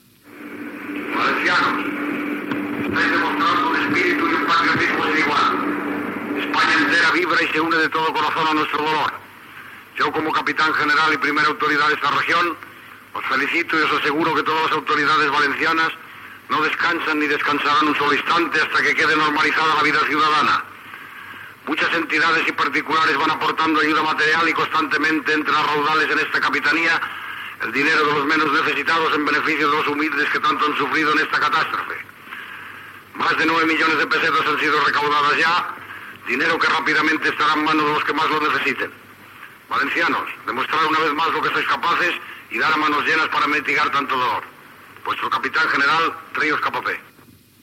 El capità general de València Joaquín Ríos Capapé s'adreça a la població valenciana dies després de les riuada del Túria (14 d'octubre de 1957).
Paraules de l'alacalde de València Tomás Trénor marqués del Túria en el dia que el cap d'Estat Francisco Franco visita València, el 25 d'octubre de 1957, dies després de les riuada del Túria.
Informatiu